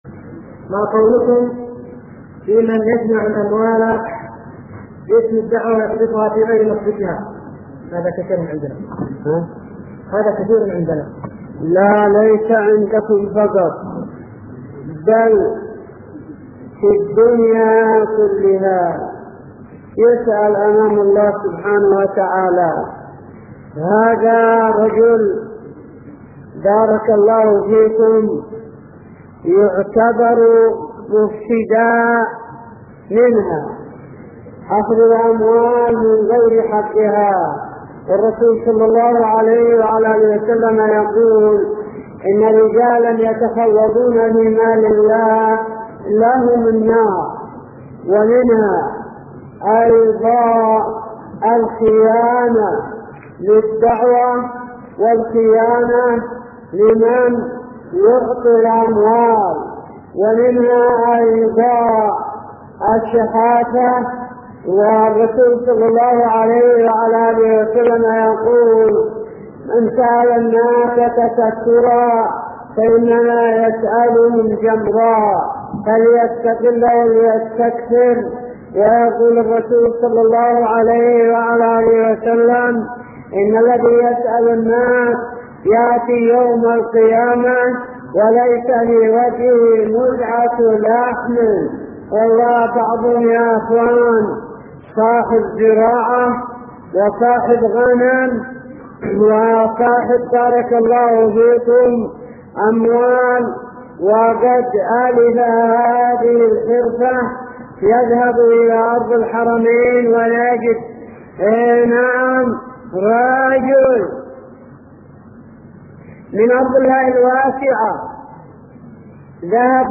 -------------- من شريط : ( أسئلة نساء السدة )